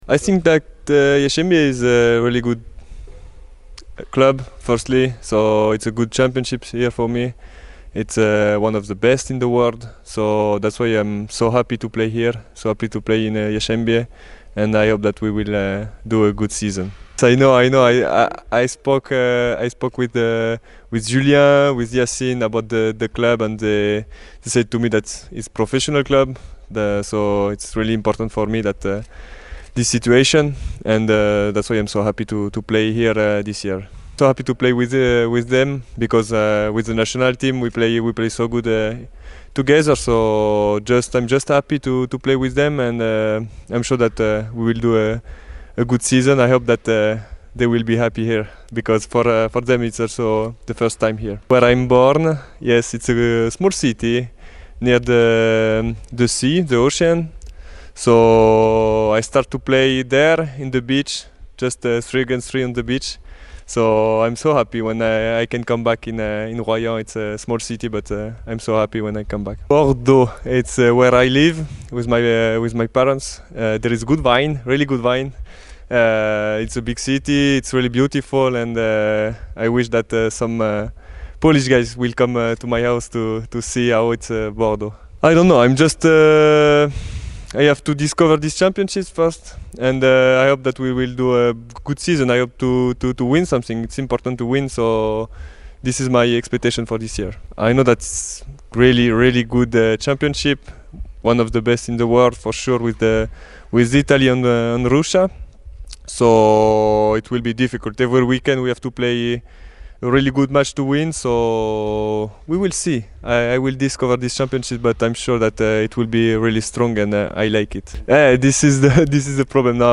[Audio] Trevor Clevenot pierwszy wywiad dla Jastrzębskiego Węgla